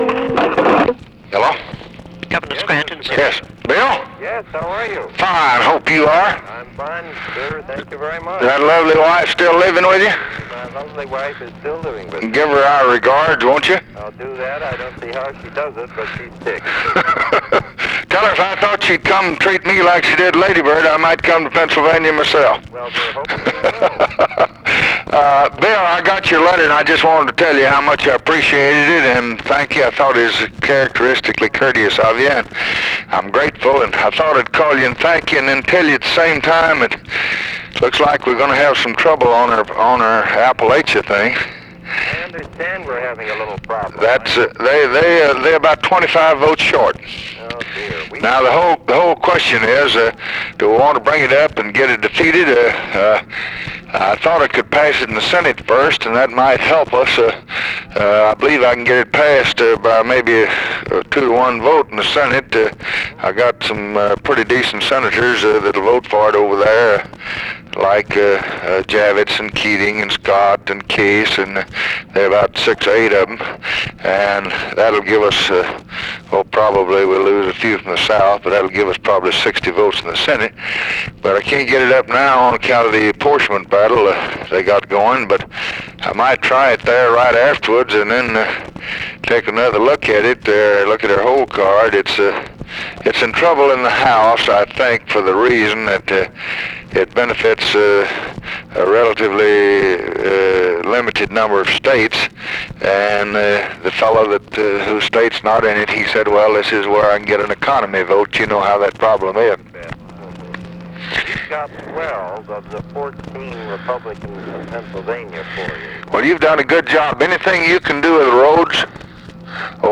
Conversation with WILLIAM SCRANTON, September 7, 1964
Secret White House Tapes